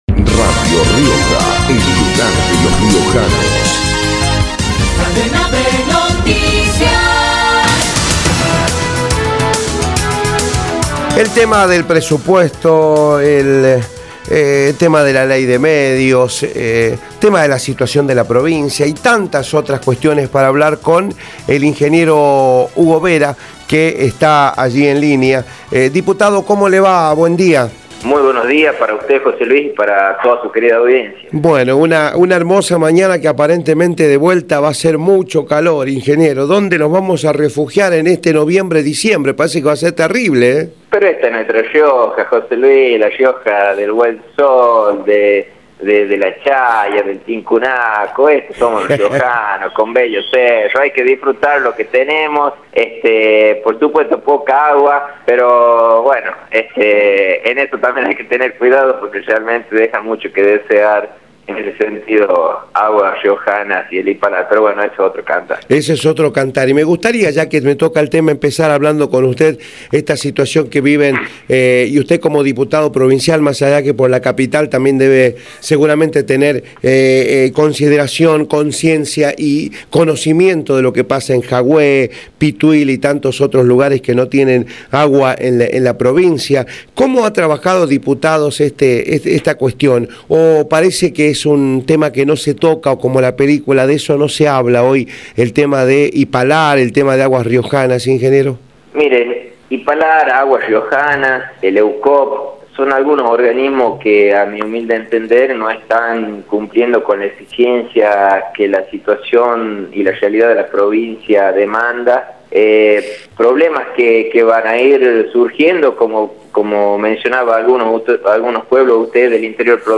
hugo-vera-diputado-provincial-por-radio-rioja.mp3